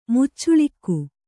♪ muccuḷikku